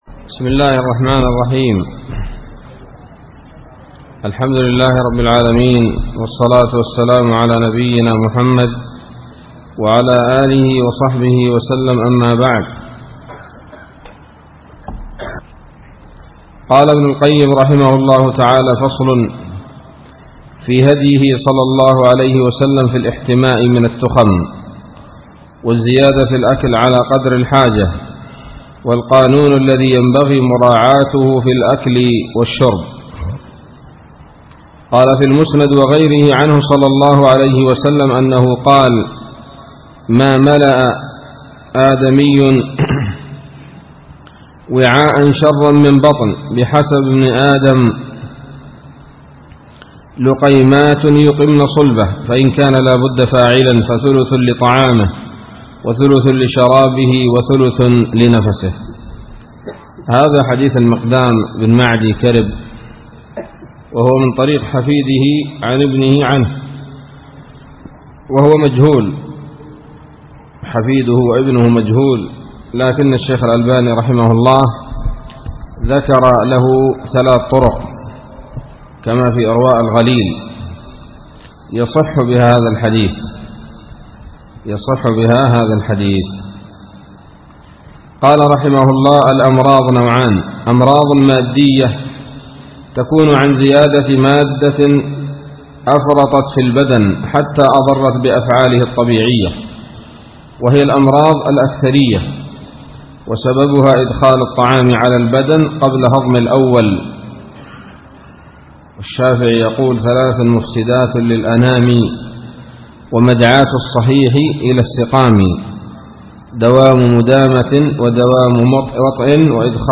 الدرس الخامس من كتاب الطب النبوي لابن القيم